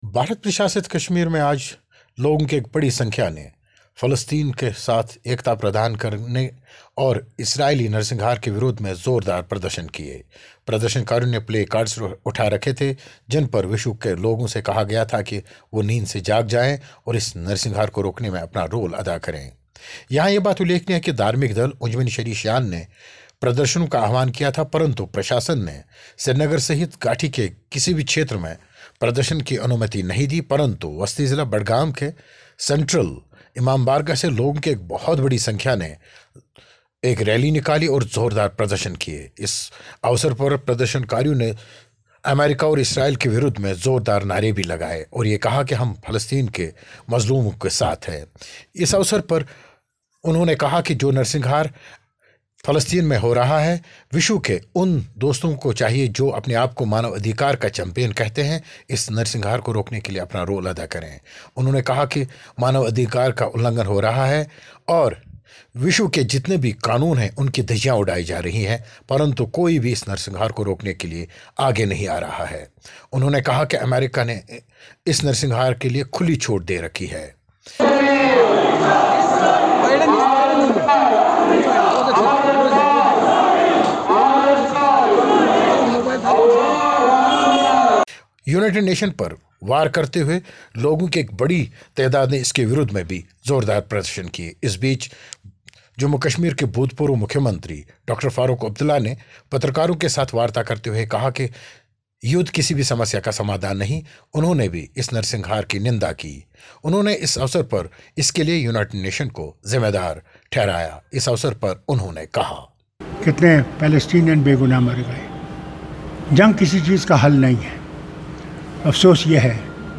फ़िलिस्तीन के समर्थन में कश्मीरियों की रैली, रिपोर्ट